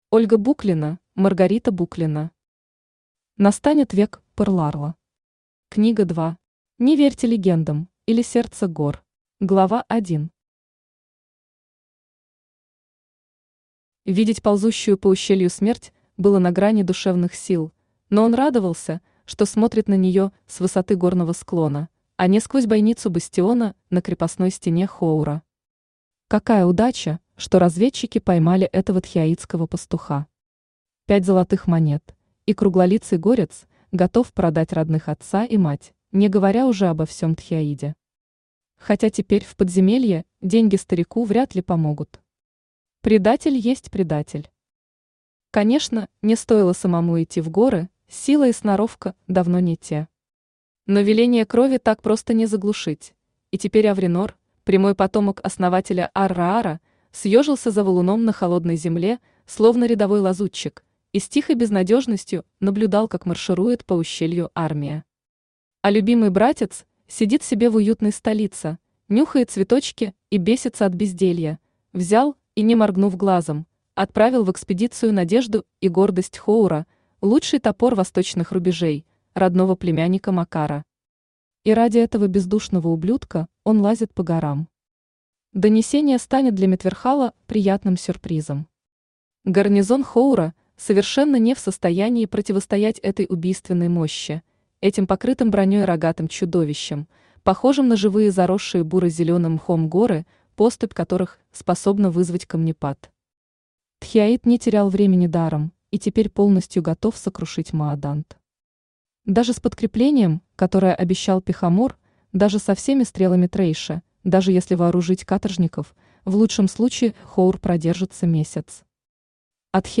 Аудиокнига Настанет век пырларла. Книга 2. Не верьте легендам, или Сердце Гор | Библиотека аудиокниг
Не верьте легендам, или Сердце Гор Автор Ольга Буклина Читает аудиокнигу Авточтец ЛитРес.